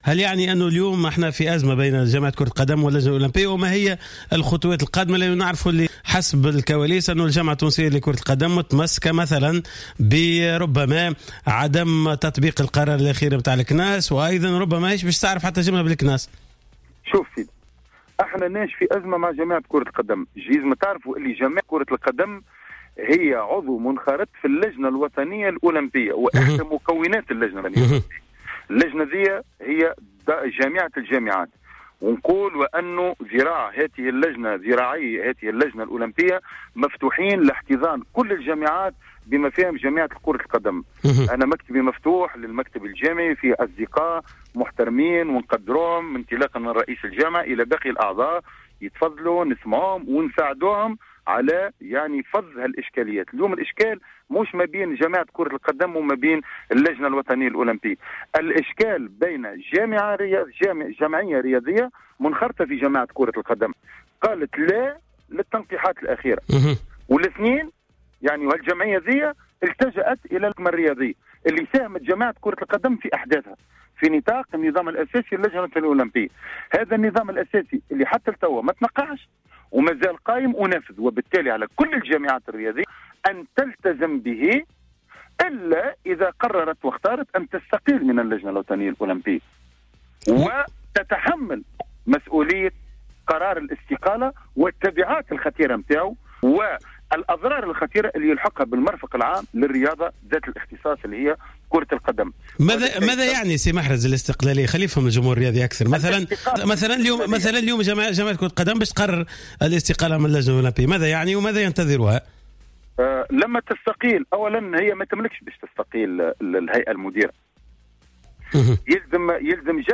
في مداخلة على برنامج راديو سبور